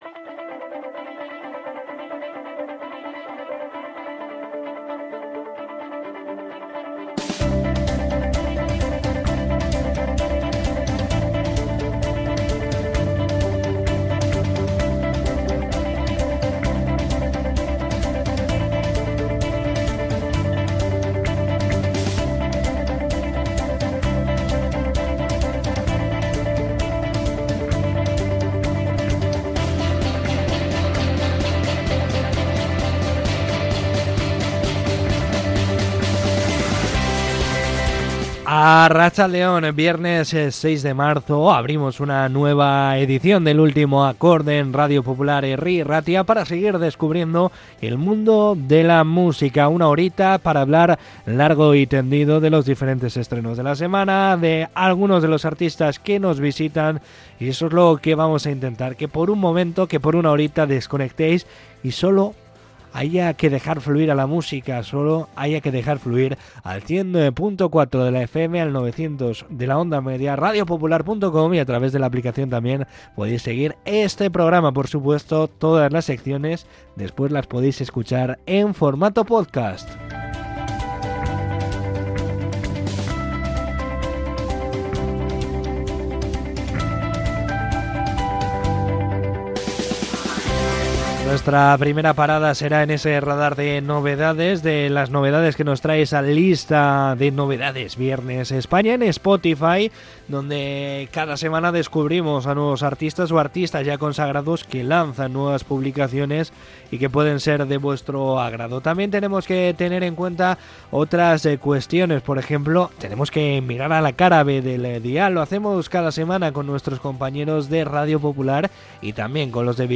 Charla con Andrés Suárez, felicitamos a David Gilmour y las mejores novedades